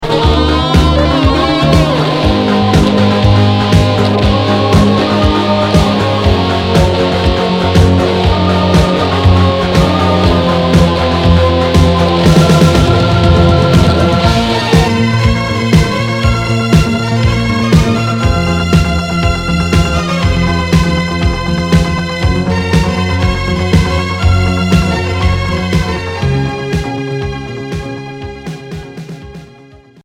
Hard FM Unique 45t